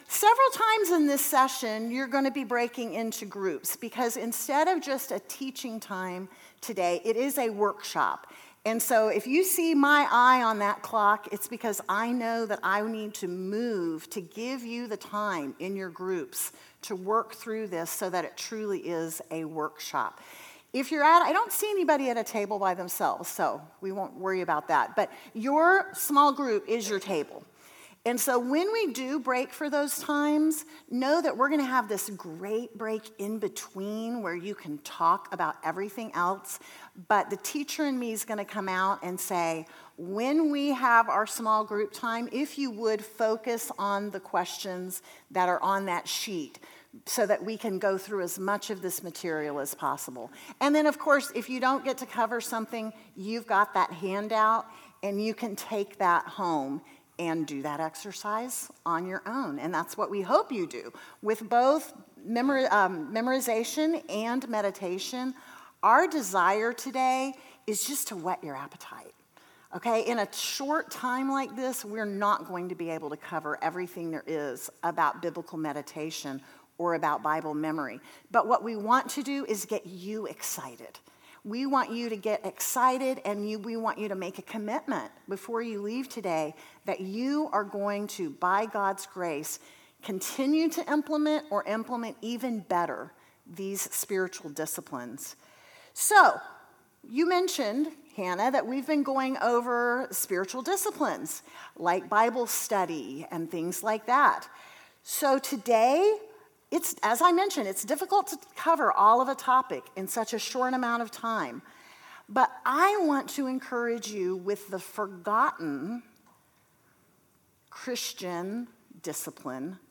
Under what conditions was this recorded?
Women Women - Summer Workshop 2025 - Meditation & Scripture Memory Audio Outline Series List Next ▶ Current 1.